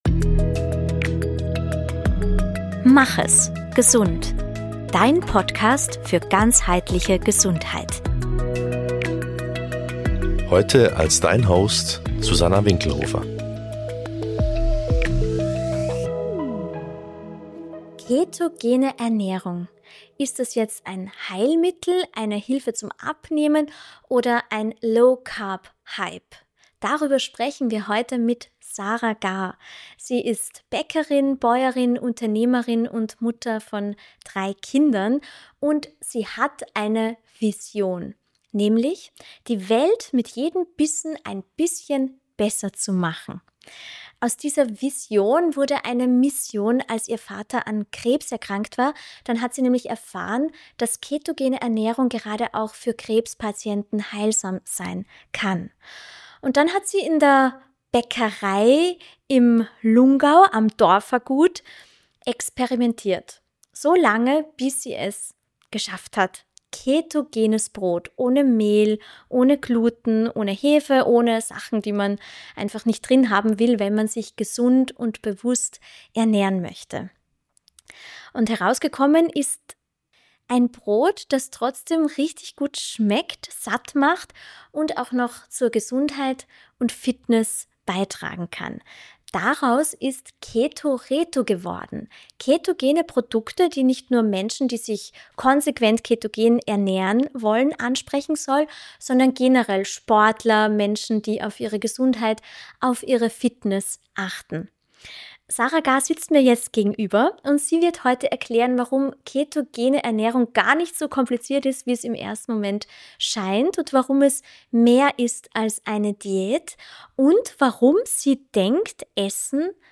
Ein praxisnahes Gespräch über Ernährung, die Heilung unterstützen kann.